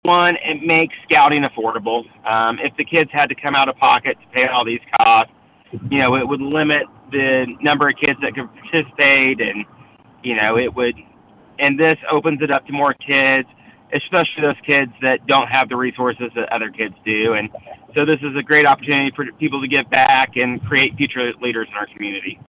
Fundraising efforts officially kicked off with the Friends of Scouting breakfast at Emporia’s Bowyer Community Building. The featured speaker, City Manager and Eagle Scout alum Trey Cocking, says Scouting was vital for setting his career path going forward — and fundraising will open up a lot of opportunities for current and future Scouts.